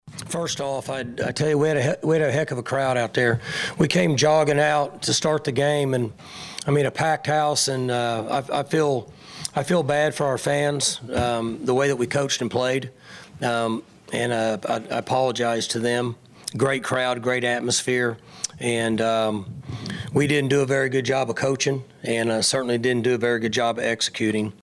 Head coach Mike Gundy gives his thoughts on the loss.